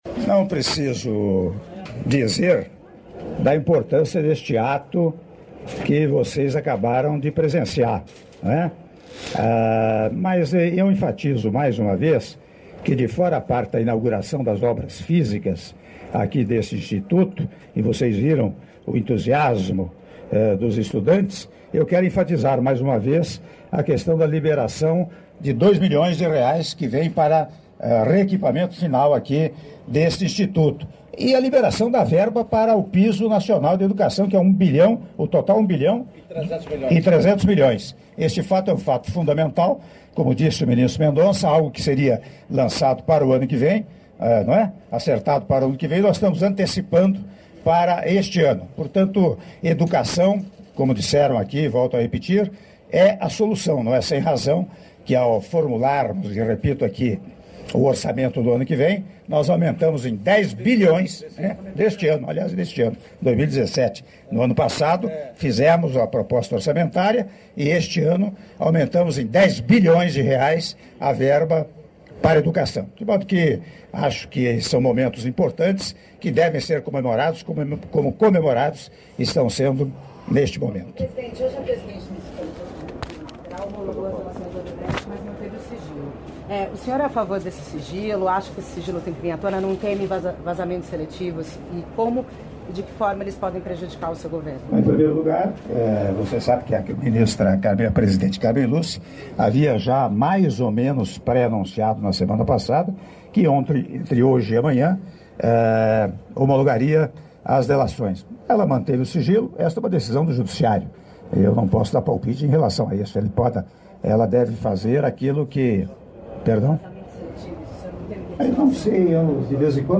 Áudio da entrevista do presidente da República, Michel Temer, após cerimônia de Inauguração do novo Campus de Serra Talhada do Instituto Federal do Sertão Pernambucano - Serra Talhada/PE (02min51s)